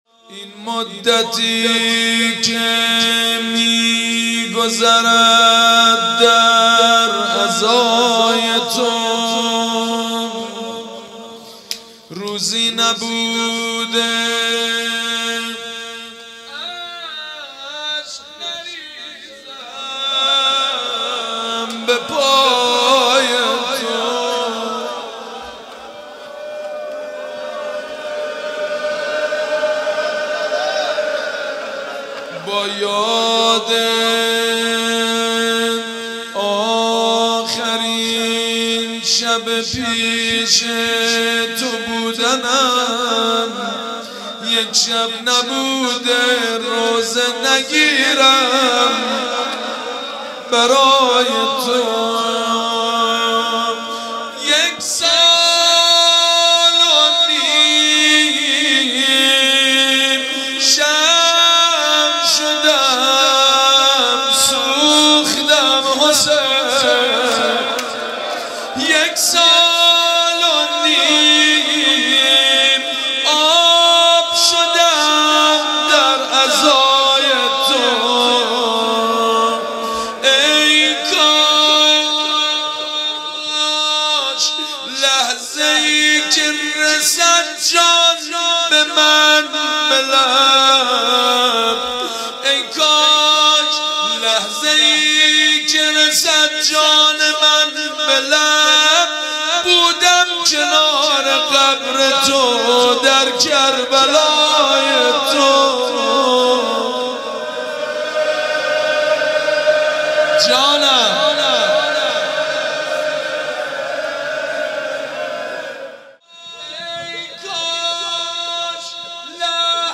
مداحی حاج سید مجید بنی فاطمه بمناسبت وفات حضرت زینب کبری سلام الله علیها